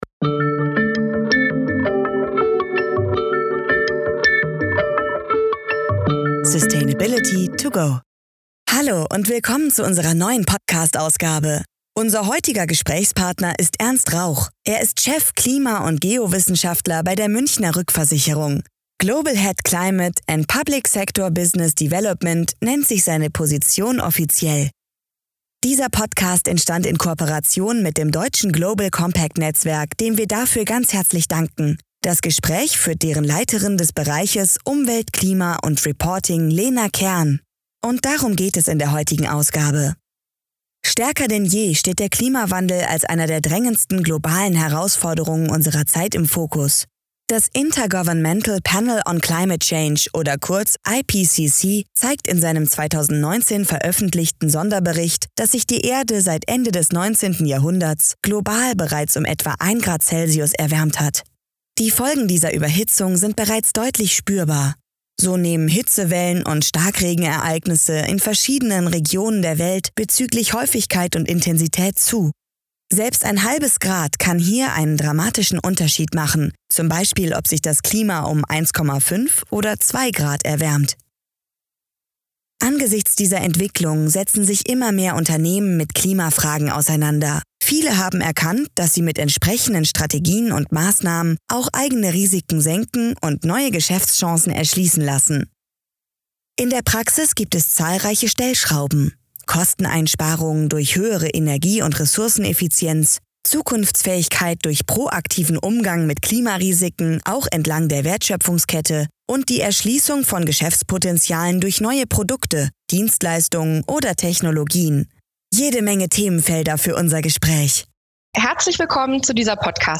Jede Menge Themenfelder für unser Gespräch!